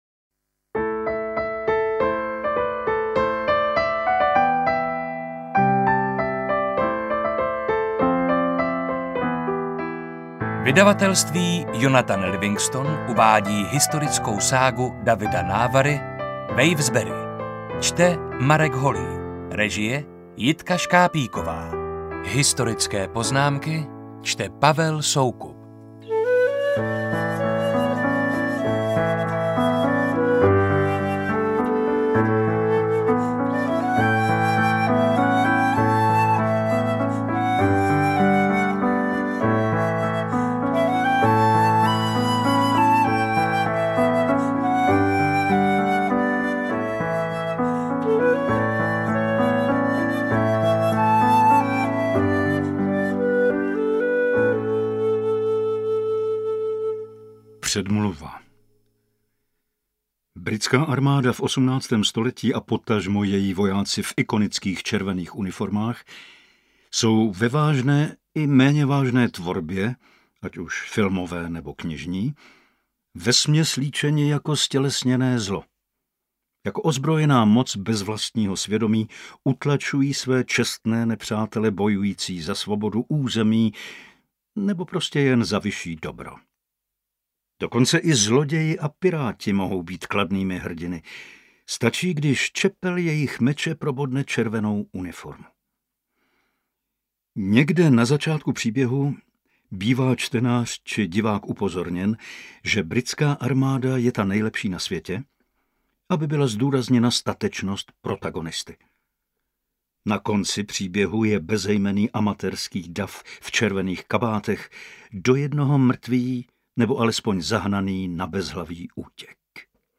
Pěkně načteno.
AudioKniha ke stažení, 31 x mp3, délka 8 hod. 17 min., velikost 454,2 MB, česky